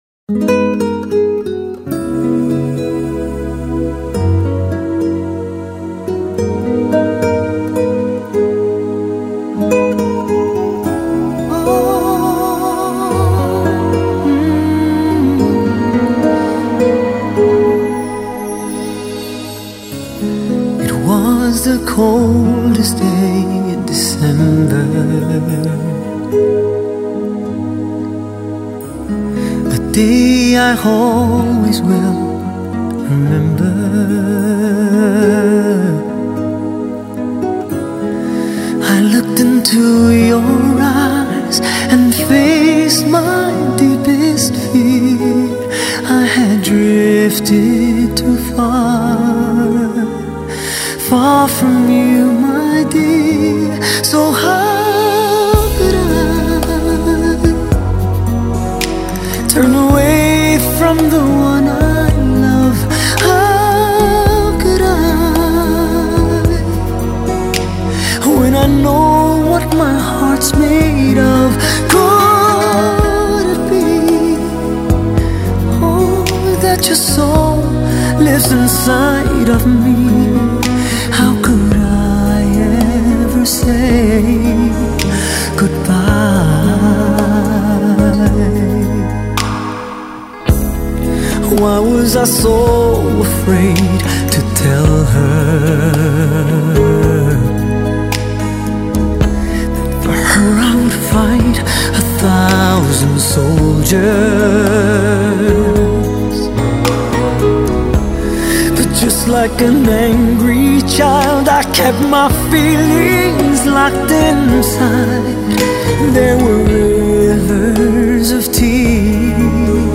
一份深情忧伤